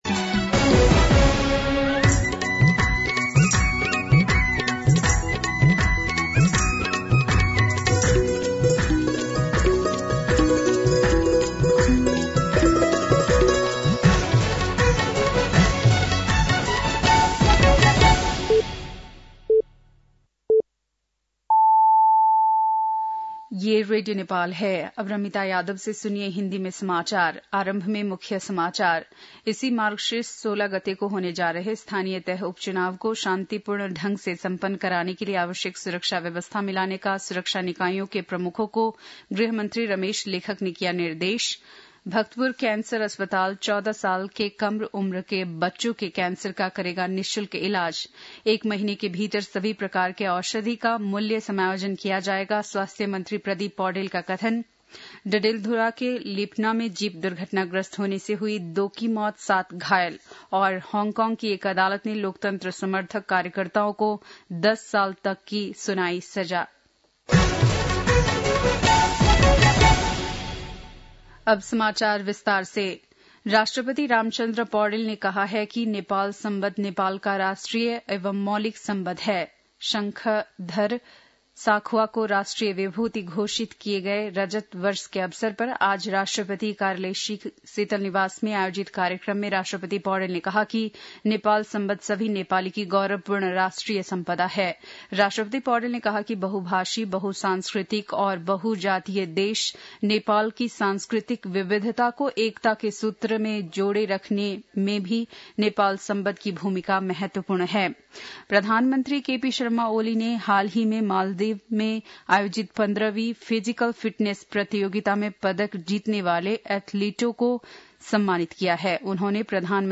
बेलुकी १० बजेको हिन्दी समाचार : ५ मंसिर , २०८१
10-PM-Hindi-News-8-4.mp3